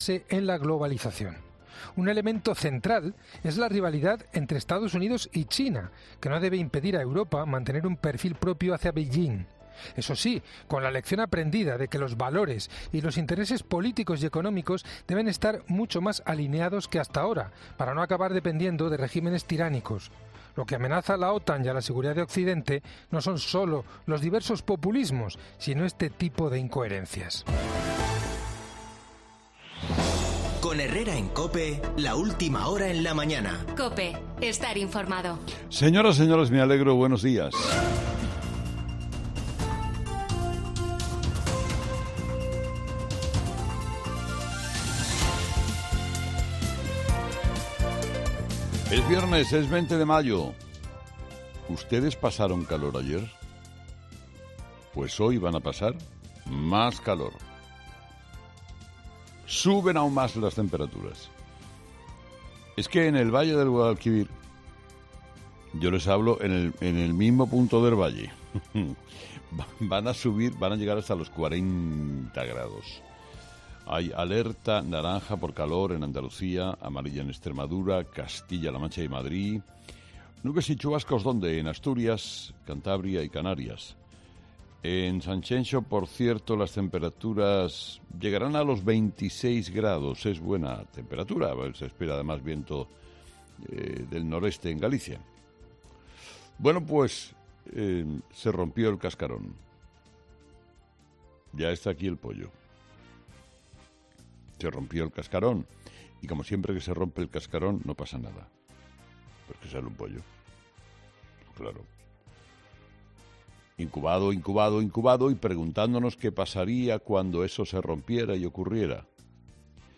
Carlos Herrera analiza los principales titulares que marcarán la actualidad de este viernes 20 de mayo
Carlos Herrera, director y presentador de 'Herrera en COPE', ha comenzado el programa de este viernes analizando las principales claves de la jornada, que pasan, entre otros asuntos, por la llegada del Rey Don Juan Carlos a España, que tuvo lugar en la tarde de este jueves.